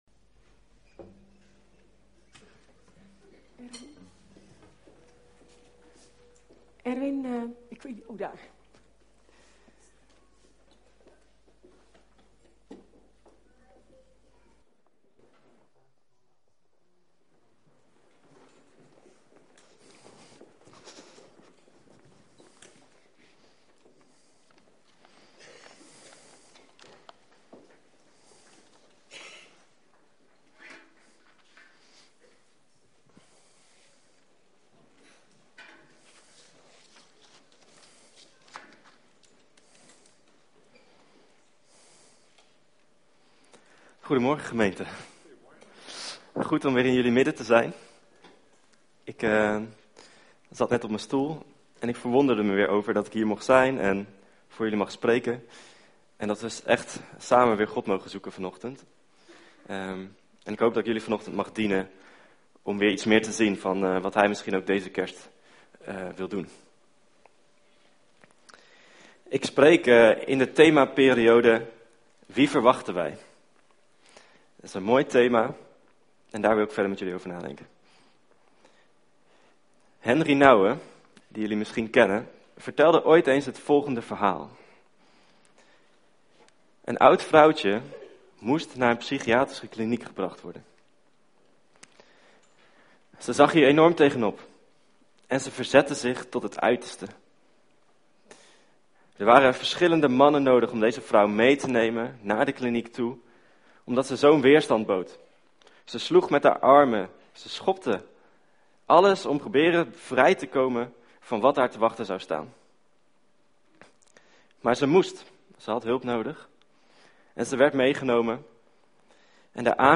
We komen elke zondagmorgen bij elkaar om God te aanbidden.